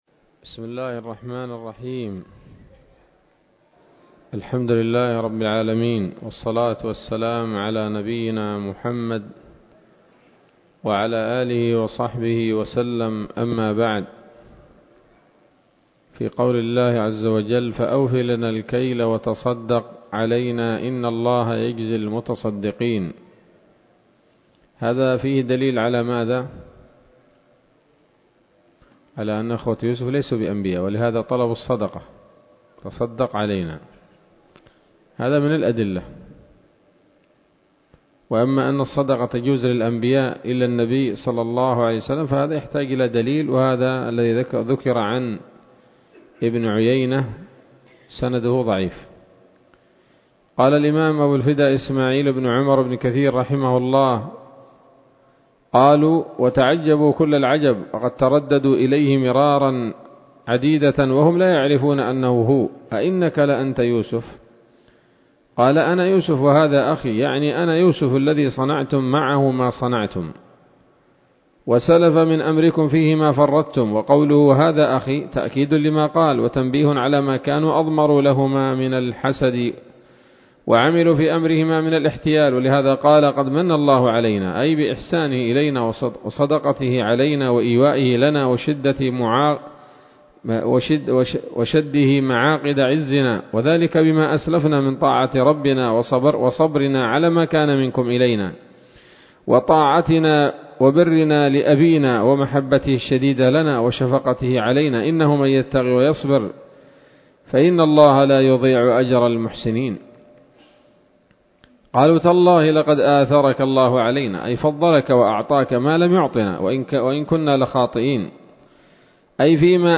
الدرس الثاني والسبعون من قصص الأنبياء لابن كثير رحمه الله تعالى